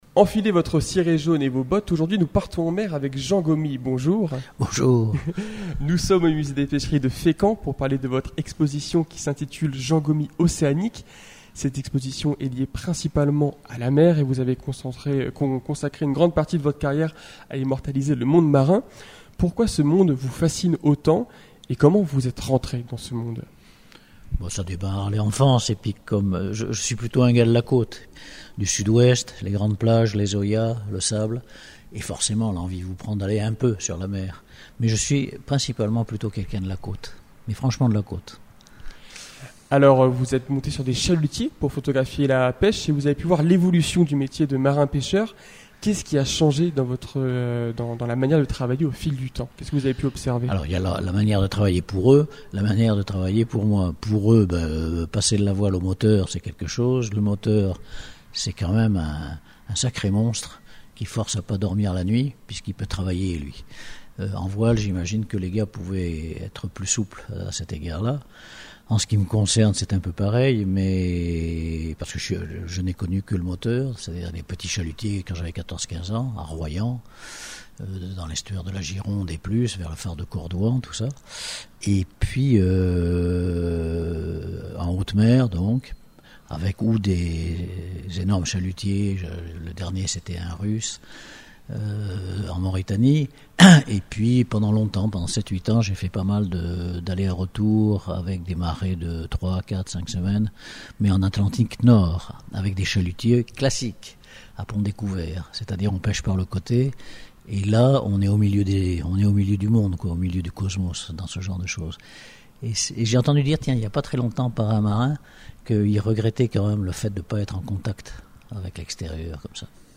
Radar s’est rendu au musée des Pêcheries de Fécamp pour y rencontrer Jean Gaumy. L’artiste y expose actuellement plusieurs de ses photographies, abordant des sujets variés tels que les boucanes à Fécamp, la pêche, sa visite d’un sous-marin nucléaire, et bien d’autres encore.
Les interviews Radar Actu Interview fécamp podcast